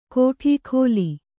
♪ kōṭhī khōli